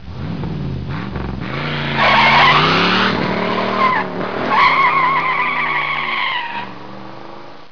دانلود صدای ماشین 40 از ساعد نیوز با لینک مستقیم و کیفیت بالا
جلوه های صوتی